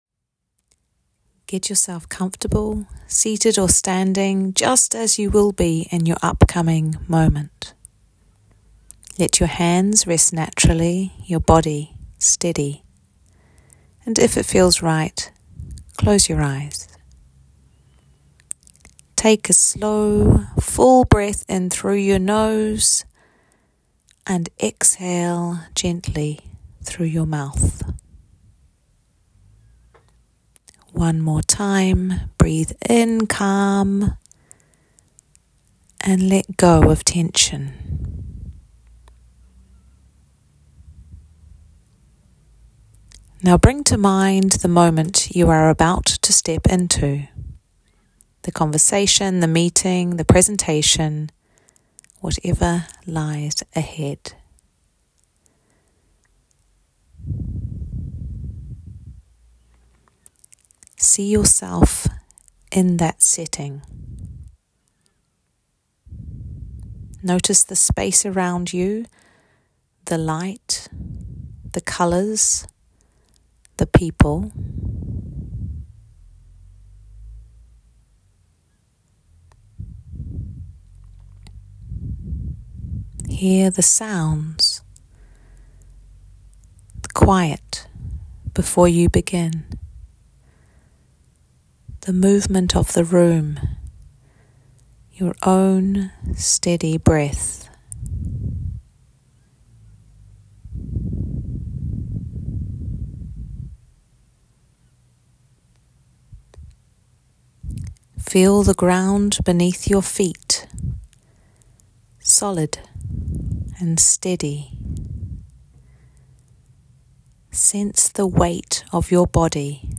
• Guided audio practices to calm your body and steady your mind
A 4-minute guided visualisation to mentally prepare for a moment where you want to feel clear, present and grounded.